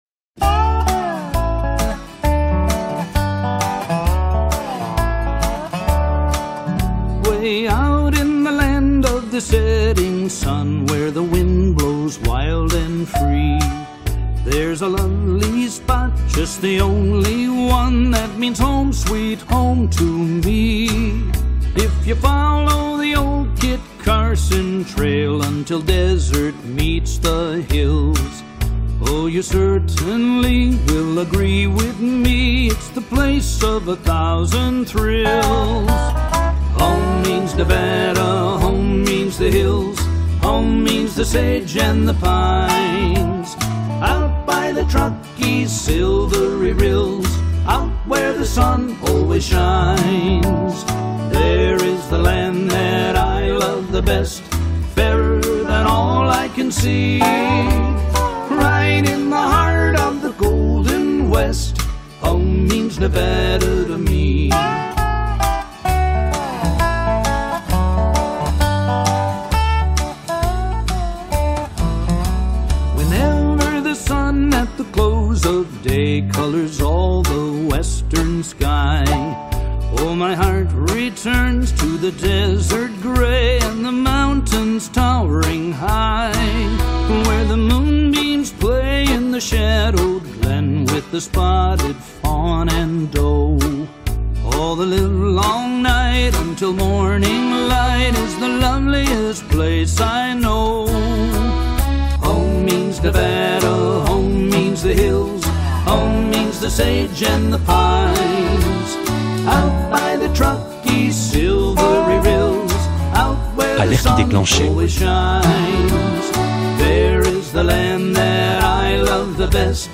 267-0a Une Chrysler dont la radio hurle